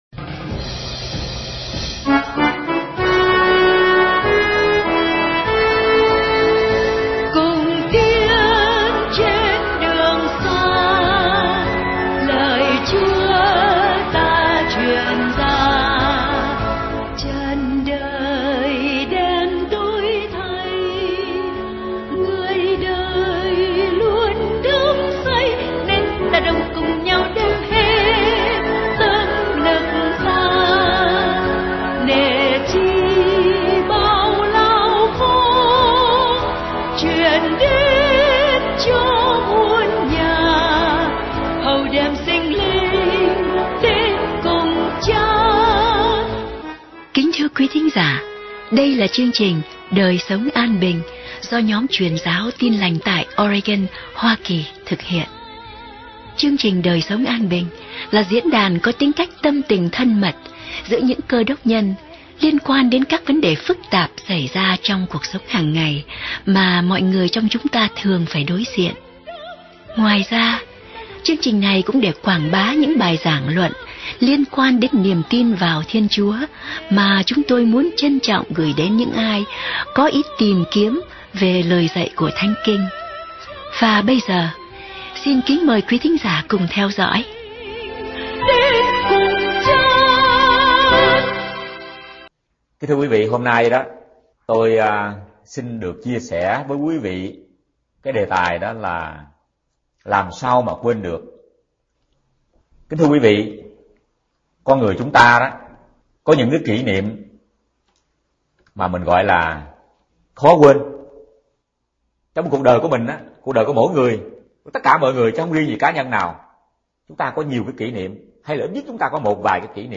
Bài giảng 23 phút Đề tài: Làm Sao Quên Được?